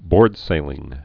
(bôrdsālĭng)